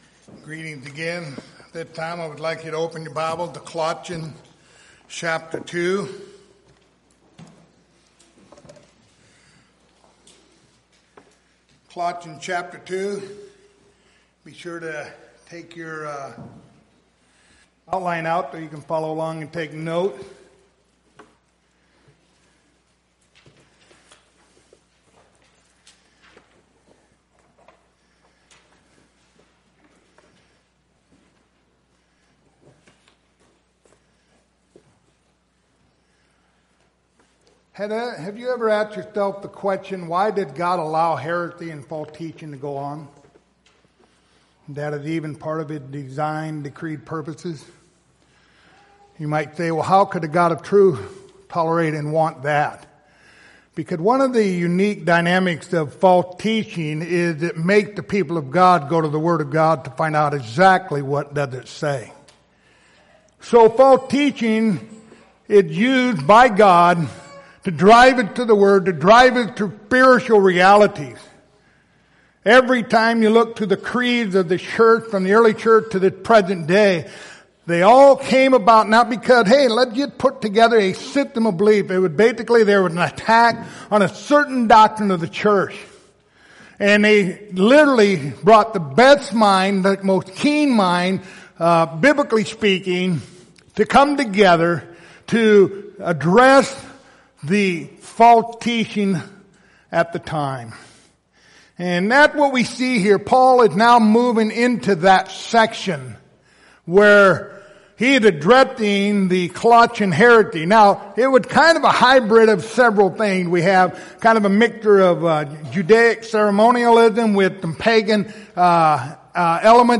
Passage: Colossians 2:11-15 Service Type: Sunday Morning